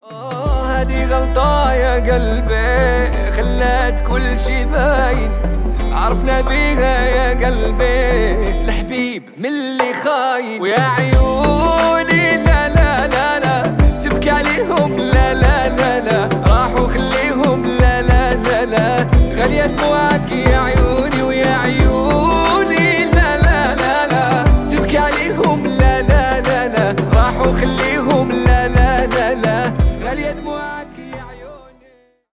Поп Музыка # арабские # восточные